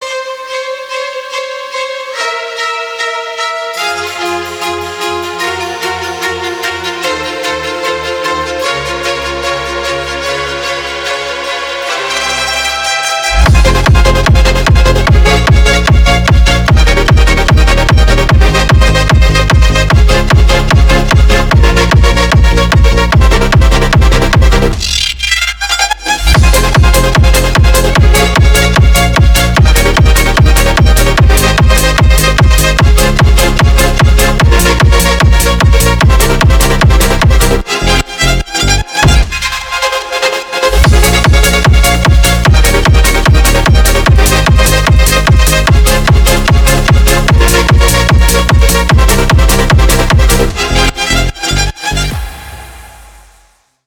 • Категория: Клубные рингтоны